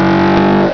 moteur1.wav